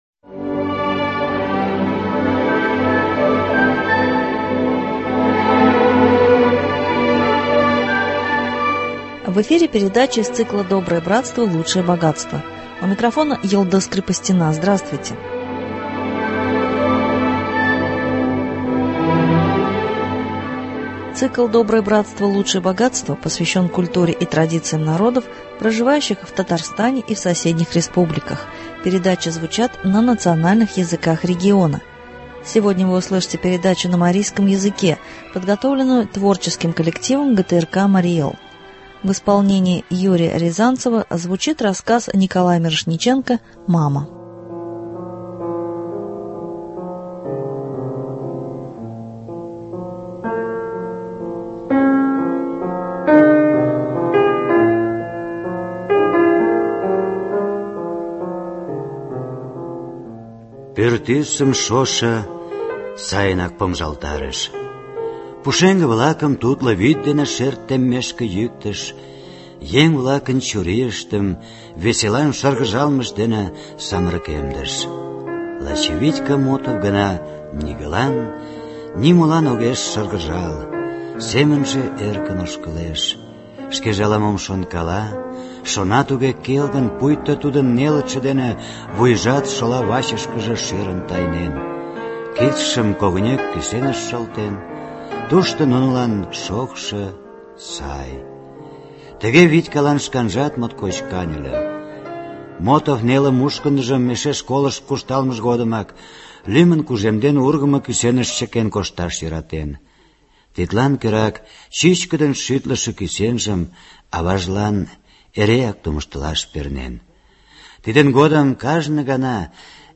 звучит рассказ Николая Мирошниченко «Мама».